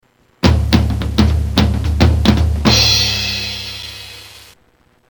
Funky Drum Roll